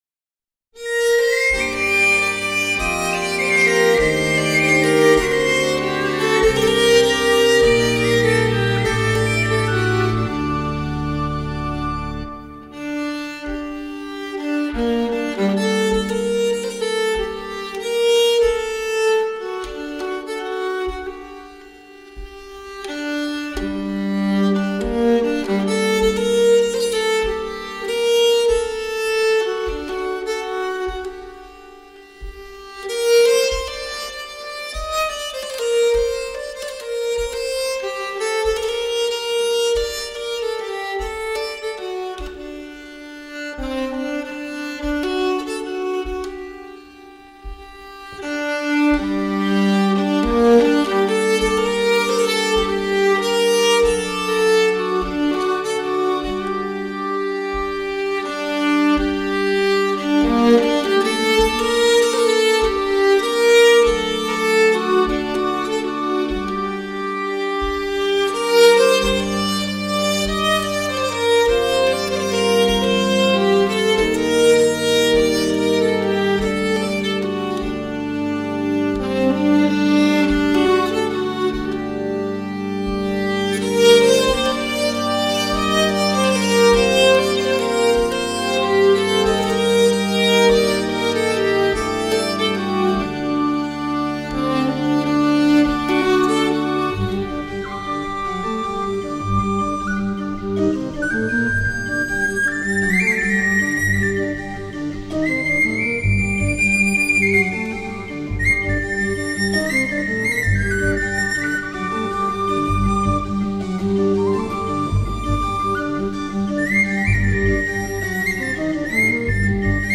融合古典、摇滚、北欧乡村风、印度民族风、苏格兰曲风、柔情女声、吟游诗歌..等多元乐风
录音定位清楚、音质细腻清晰、音像深度及宽度精采可期
透过人声、小提琴、萨克斯风、双簧管、贝斯、吉他、钢琴、打击乐器、印度笛、竖笛、扬琴、
手风琴、印度琵琶、低音喇叭等多种乐器及灵感泼洒的独特编曲手法，